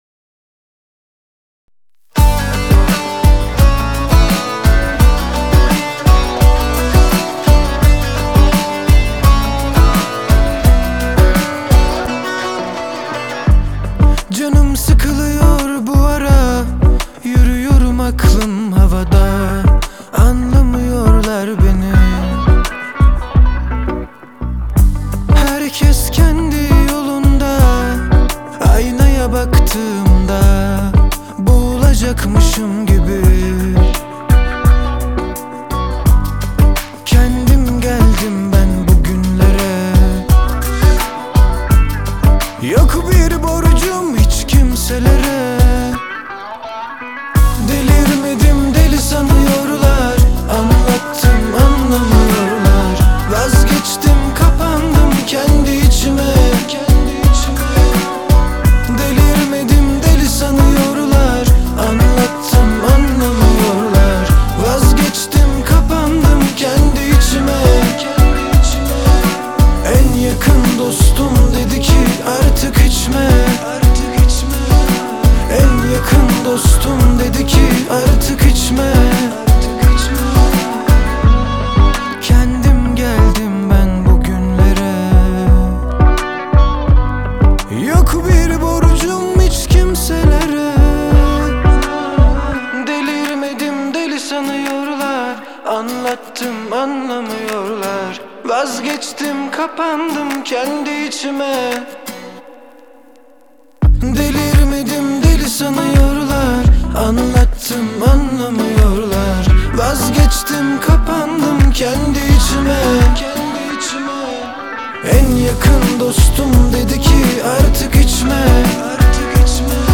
Трек размещён в разделе Турецкая музыка / Альтернатива.